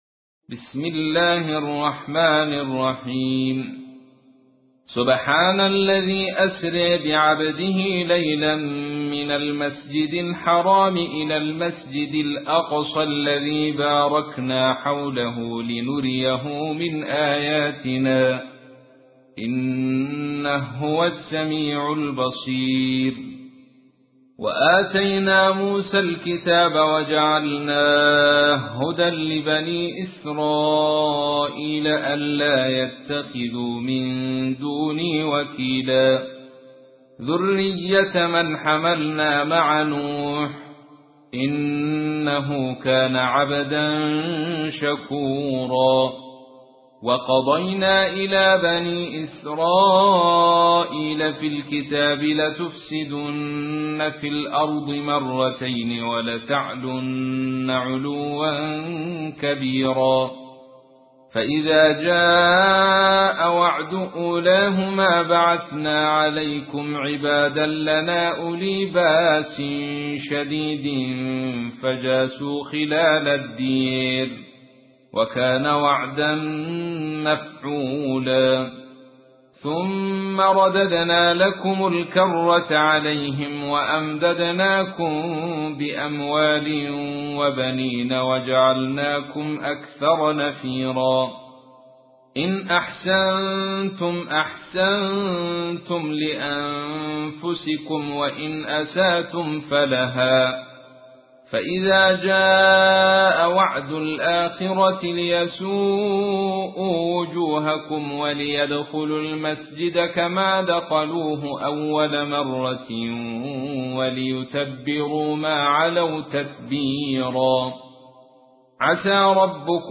تحميل : 17. سورة الإسراء / القارئ عبد الرشيد صوفي / القرآن الكريم / موقع يا حسين